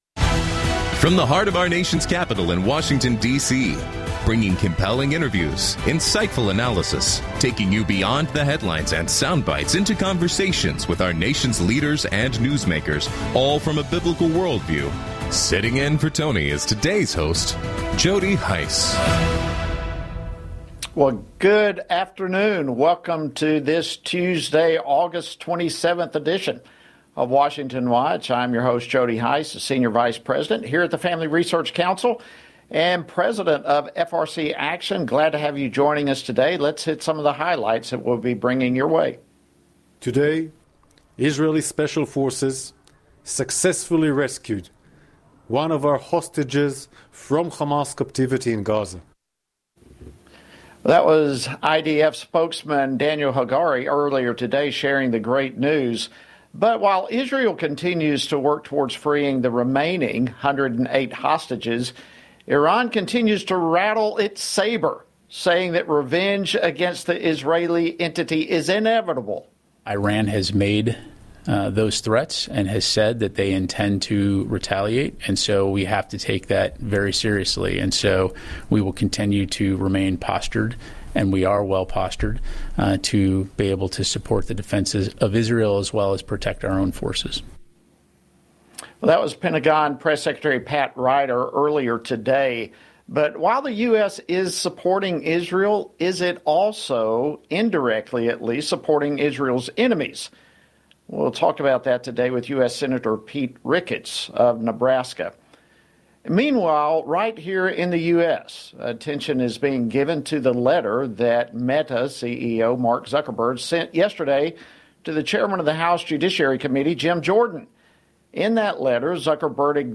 On today’s program, hosted by Jody Hice: Andy Biggs, U.S. Representative for the 5th District of Arizona, comments on Meta CEO Mark Zuckerberg’s letter admitting he caved to the Biden administration’s pressure campaign to censor alleged COVID-19 misinformation.